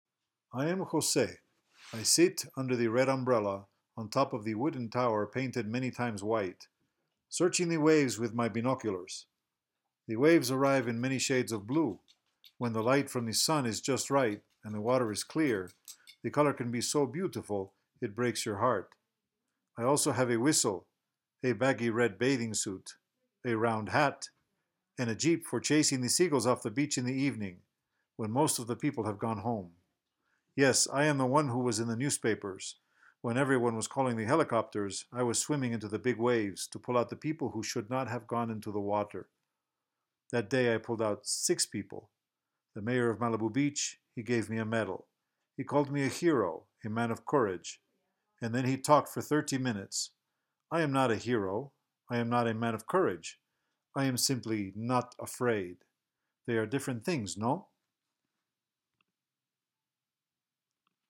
prologue_lightaccent.m4a